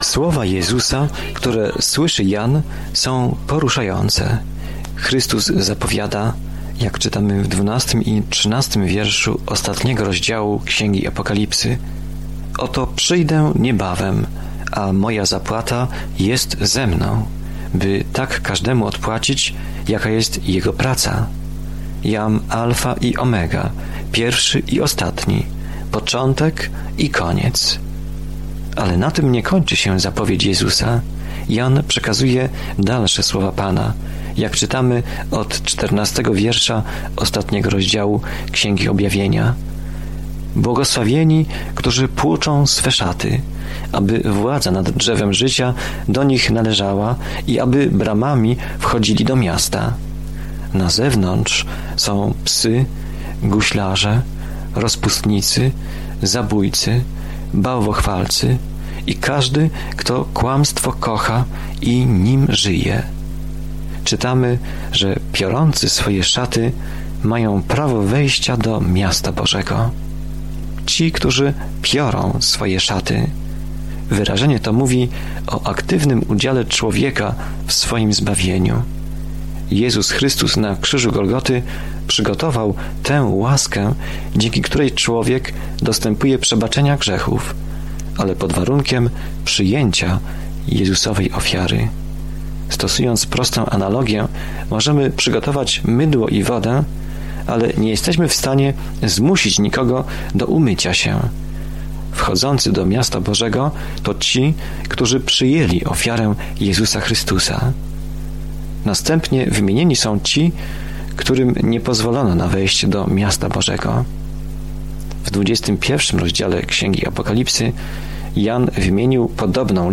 Scripture Revelation 22:11-17 Day 44 Start this Plan Day 46 About this Plan Apokalipsa św. Jana opisuje koniec rozległego planu dziejów, przedstawiając obraz tego, jak zło zostanie ostatecznie uporane, a Pan Jezus Chrystus będzie rządził z całą władzą, mocą, pięknem i chwałą. Codziennie podróżuj przez Objawienie, słuchając studium audio i czytając wybrane wersety słowa Bożego.